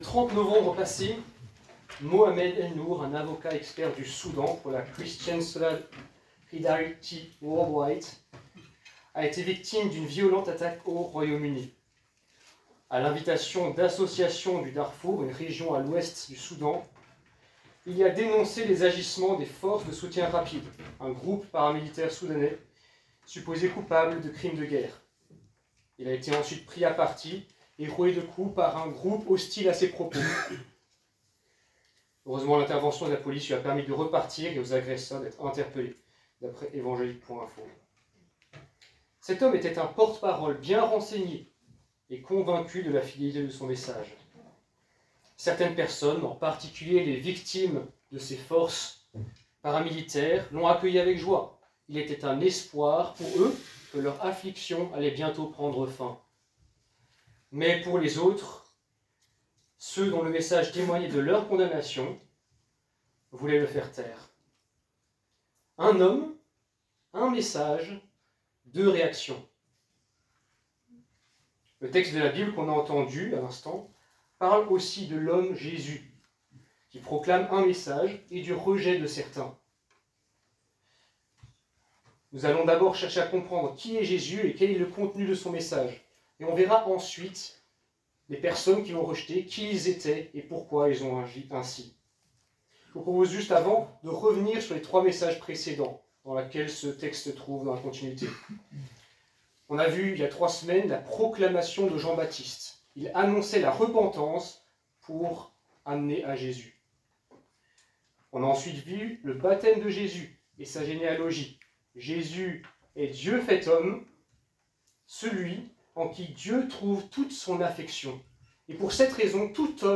predication-du-14dec.mp3